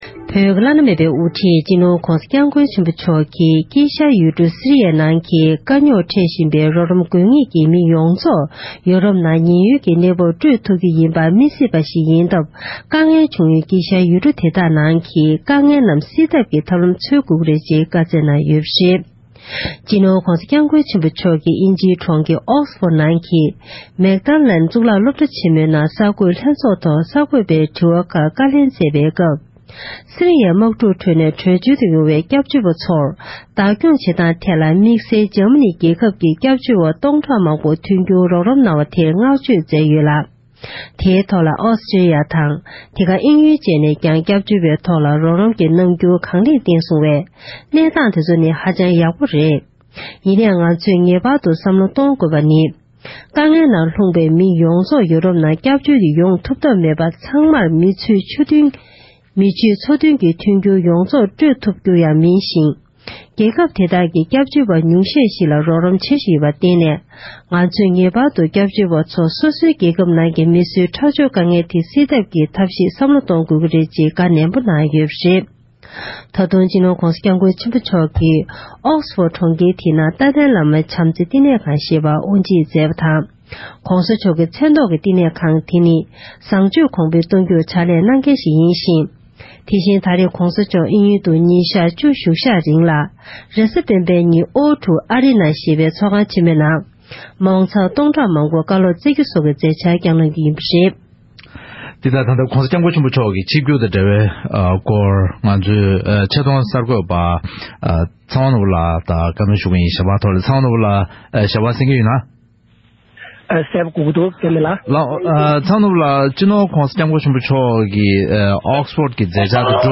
གསར་འགྱུར
བཀའ་འདྲི